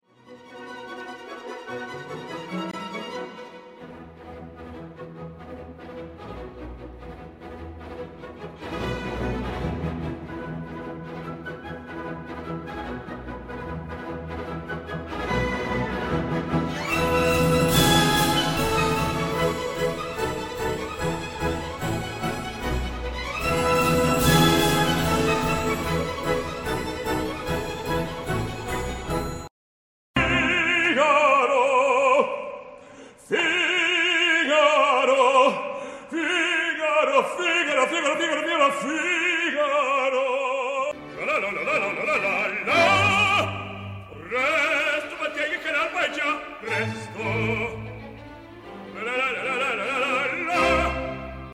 I’ll bet you have heard these excerpts from operas, but maybe didn’t know they were operas by Rossini!